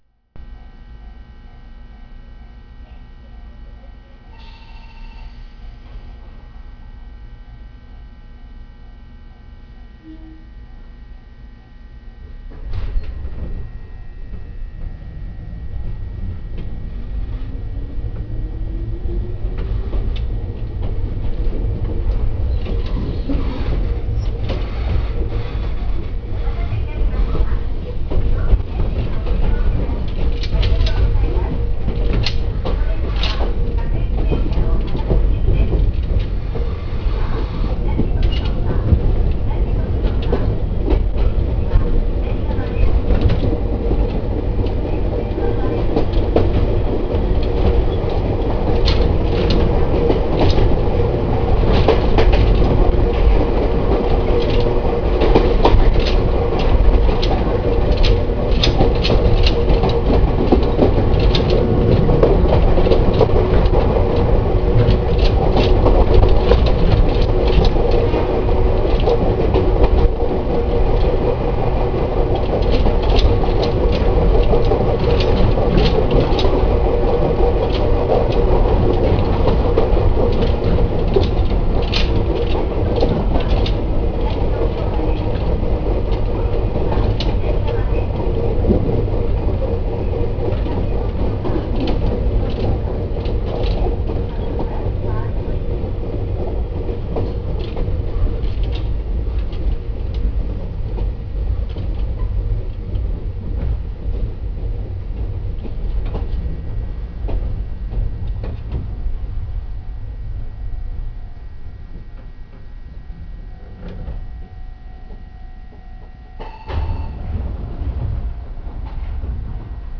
・150形走行音
【上信線】上州富岡→東富岡（2分0秒：655KB）
収録は155編成で元701系の編成となりますが性能は3本で同一。抵抗制御で、自動放送とドアチャイムが設置されているのが西武時代からの変化と言えそうです。